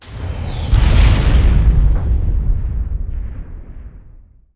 大型宇宙飞船运动中碰撞-YS070515.wav
通用动作/06交通工具/大气层外飞行器类/大型宇宙飞船运动中碰撞-YS070515.wav
• 声道 單聲道 (1ch)